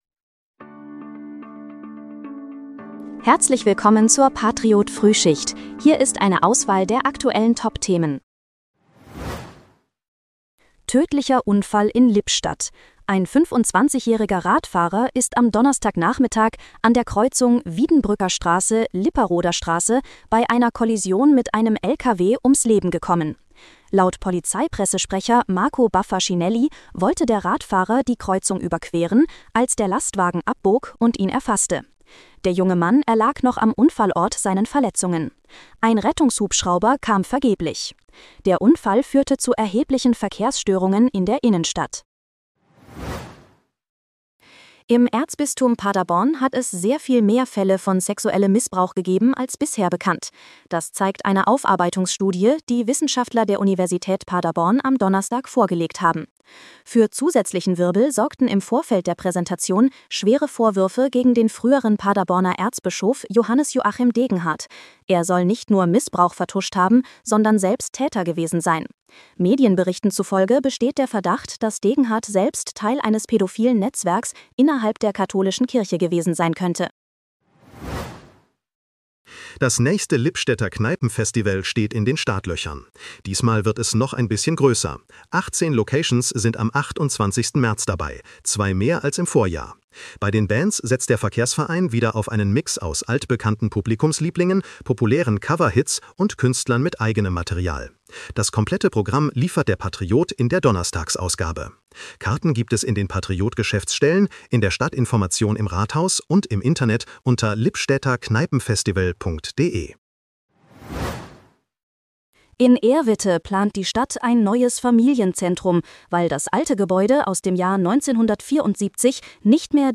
Willkommen zur Patriot-Frühschicht. Dein morgendliches News-Update
mit Hilfe von Künstlicher Intelligenz.